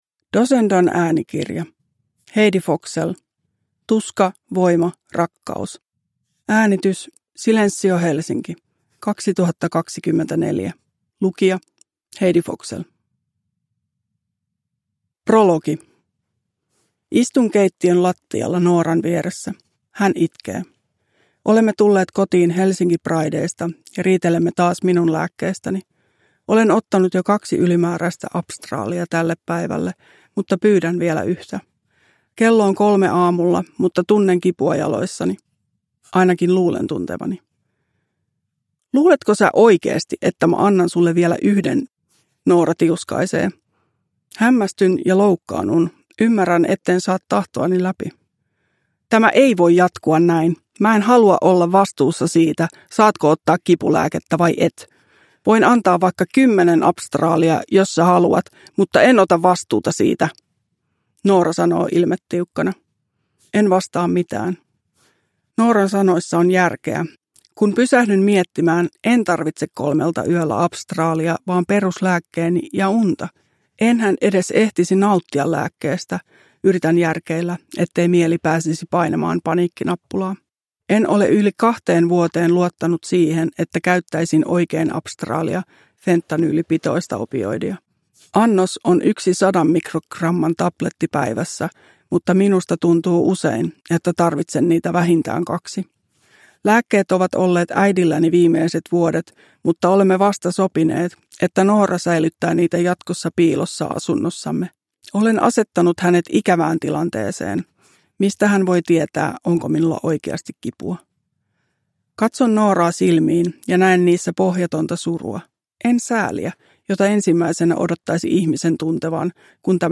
Tuska, voima, rakkaus – Ljudbok